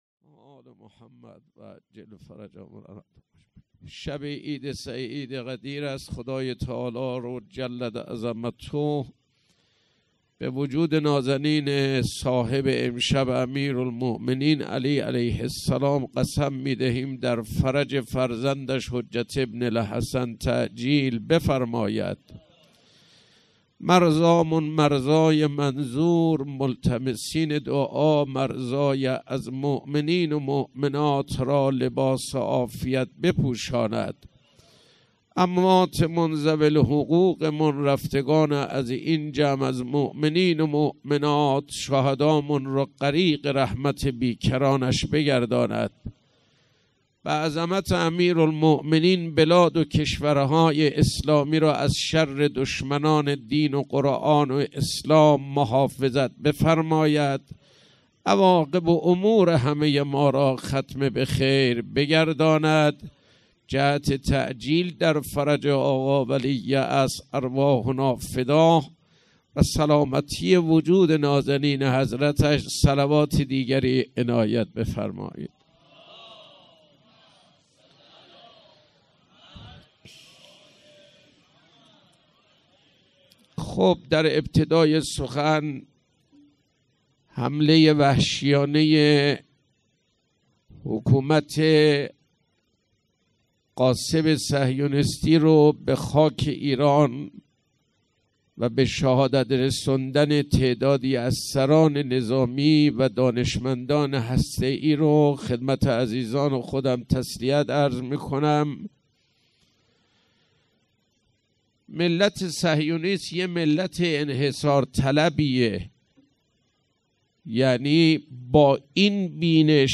سخنرانی
شب دوم جشن عید غدیر ۱۴۰۴